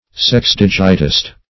sexdigitist.mp3